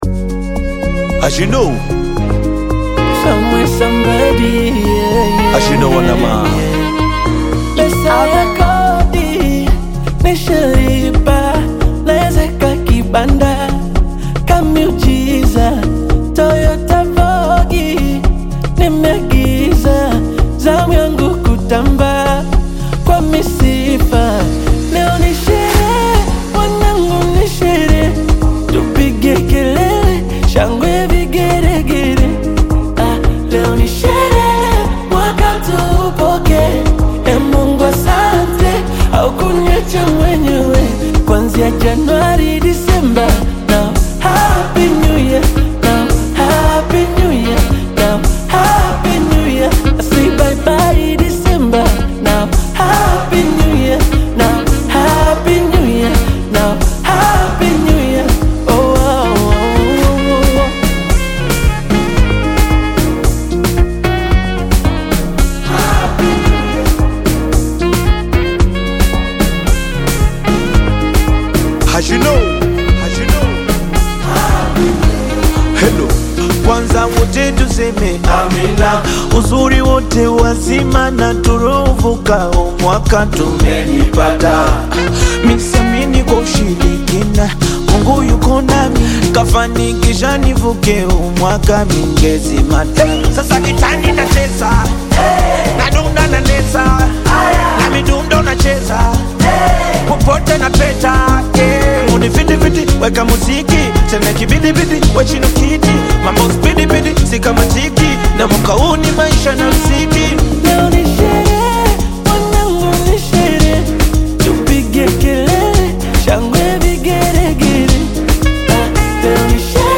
upbeat Bongo Flava/Amapiano track
Genre: Amapiano